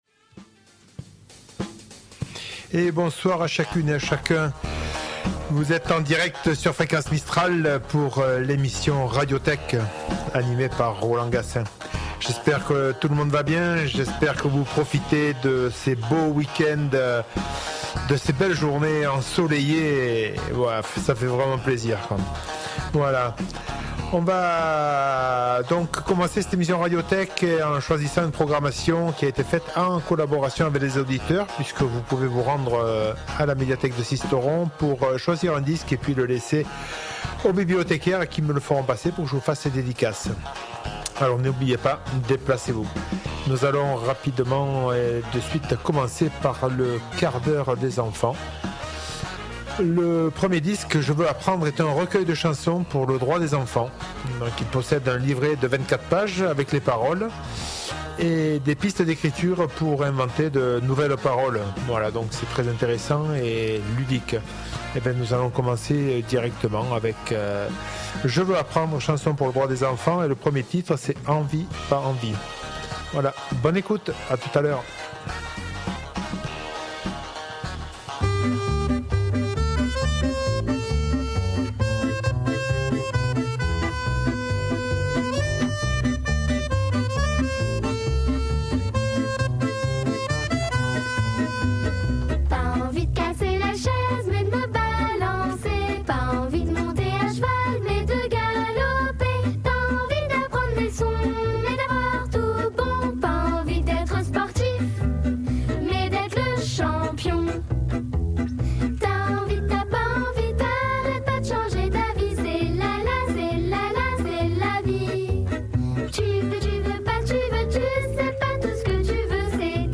Le tout agrémenté par de la chanson pour enfants en début de soirée, du jazz, du blues, de la bonne chanson française et du classique pour terminer l'émission en beauté.